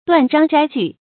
斷章摘句 注音： ㄉㄨㄢˋ ㄓㄤ ㄓㄞ ㄐㄨˋ 讀音讀法： 意思解釋： 裁斷章節，取綴成文。